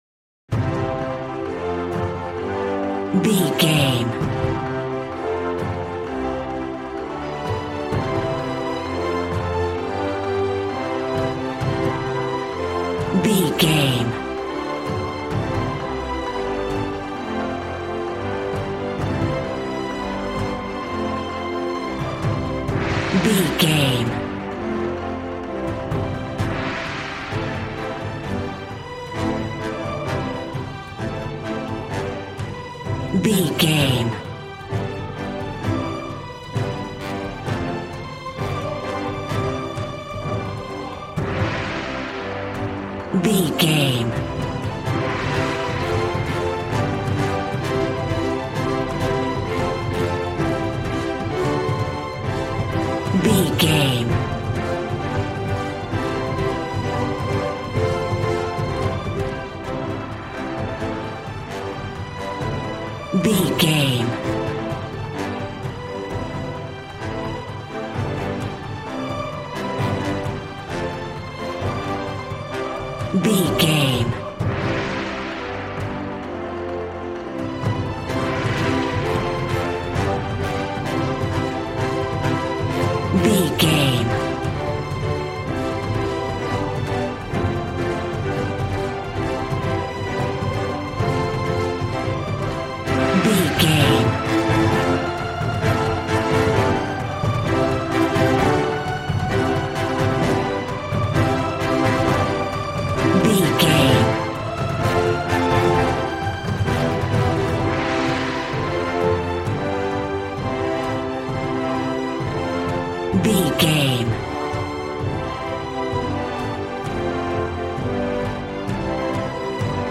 Action and Fantasy music for an epic dramatic world!
Ionian/Major
groovy
drums
bass guitar
electric guitar